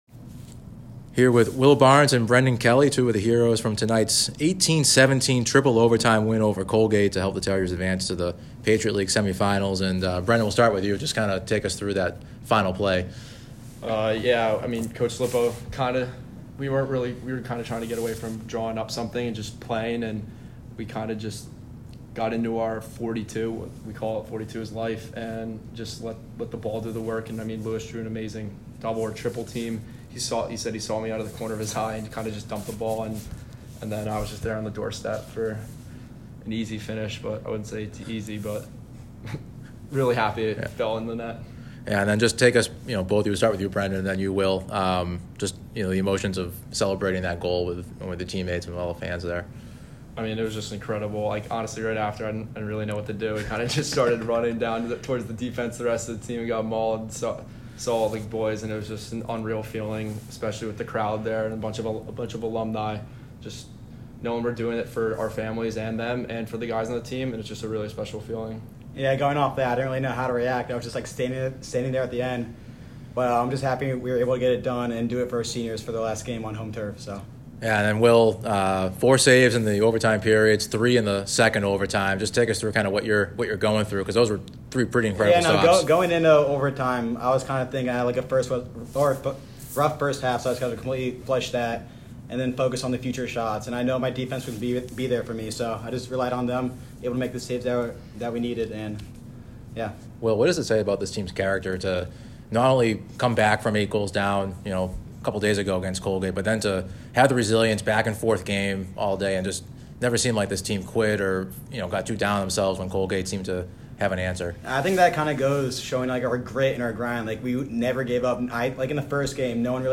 Men's Lacrosse / PL Quarterfinal Postgame Interview